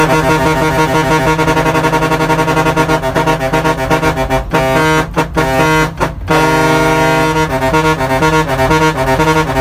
Accordion Efeito Sonoro: Soundboard Botão
Accordion Botão de Som